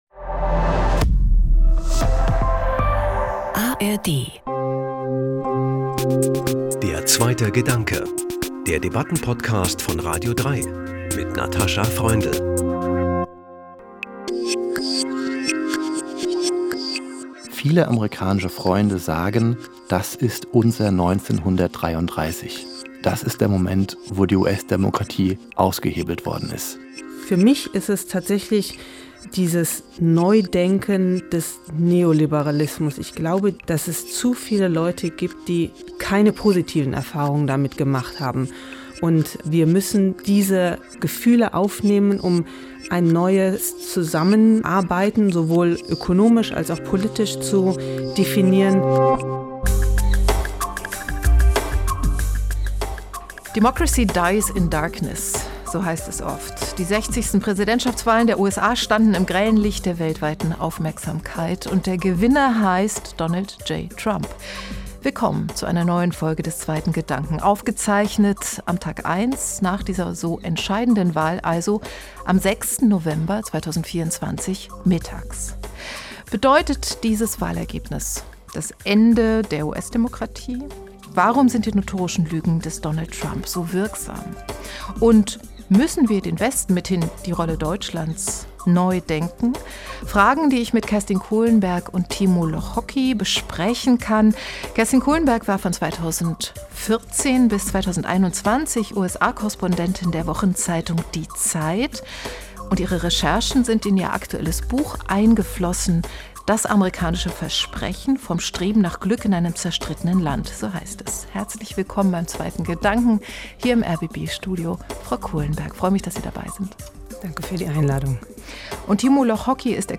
Am 6. November 2024 mittags, dem Tag nach der Wahlnacht, zeichnen wir diese Podcast-Folge auf – und der klare Wahlsieg für Donald Trump steht fest: ein von seiner Partei, den Republikanern, fast kultisch gefeierter Ex-Präsident, ein notorischer Lügner, Hochstapler und verurteilter Sexualstraftäter.